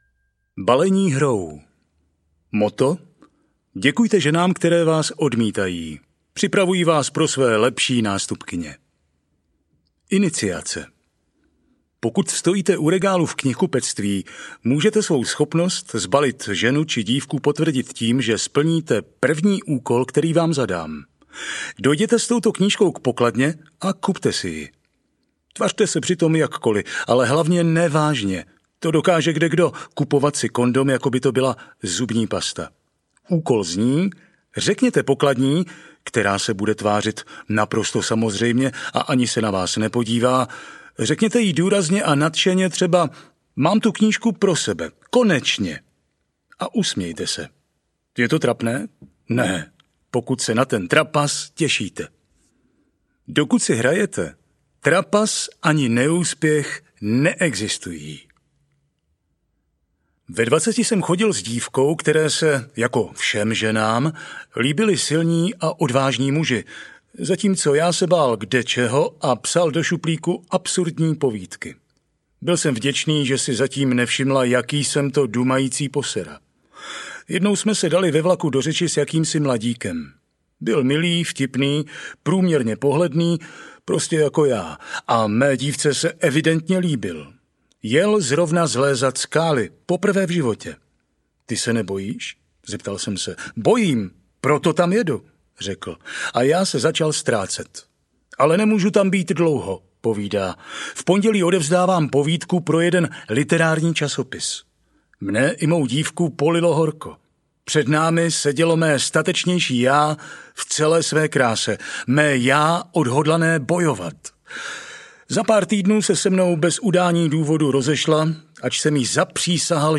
Jak sbalit ženu 2.0 audiokniha
Ukázka z knihy